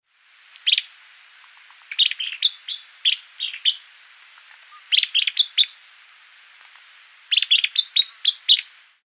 TAWNY-CROWNED PYGMY-TYRANT Euscarthmus meloryphus
EuscarthmusmelacoryphusLagunaBlanca11JUN00MVQZ.mp3